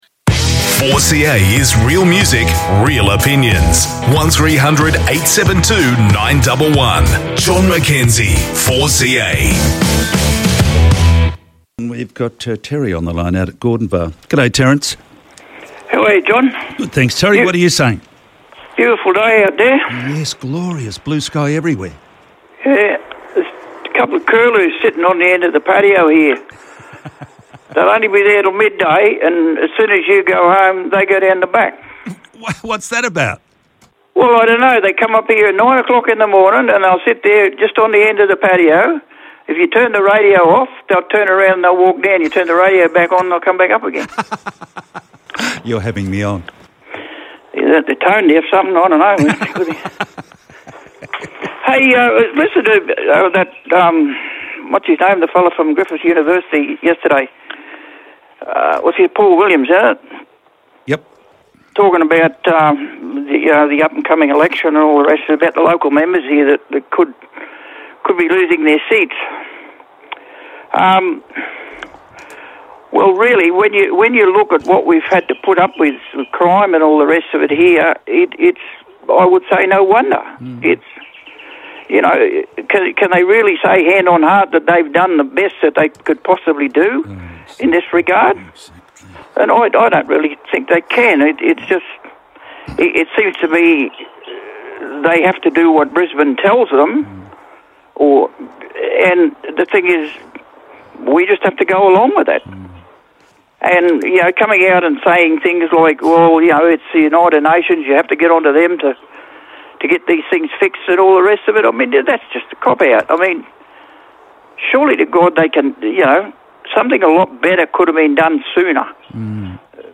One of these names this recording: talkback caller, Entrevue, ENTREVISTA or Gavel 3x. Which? talkback caller